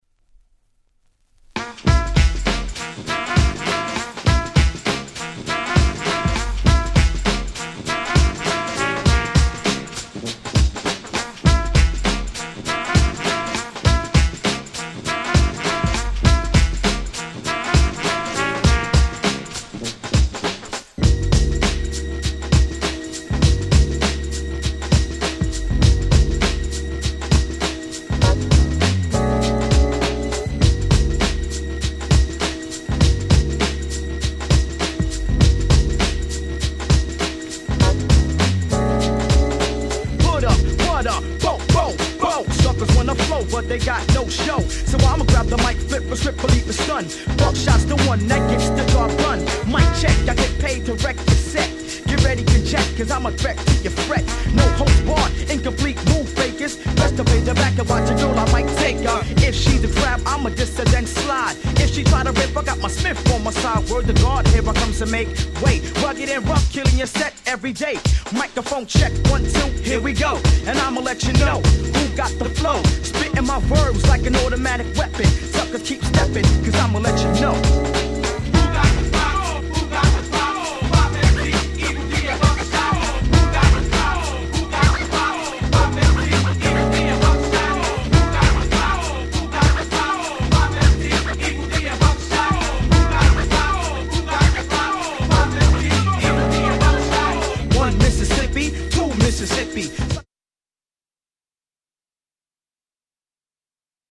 【Media】Vinyl 12'' Single
90's Hip Hop Classic !!